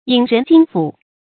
郢人斤斧 注音： ㄧㄥˇ ㄖㄣˊ ㄐㄧㄣ ㄈㄨˇ 讀音讀法： 意思解釋： 同「郢匠揮斤」。